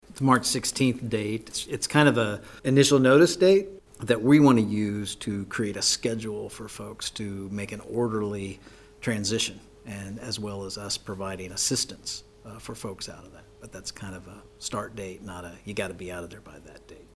Manhattan City Manager Ron Fehr says it’s actually more of a start date for residents to begin planning their next course of action.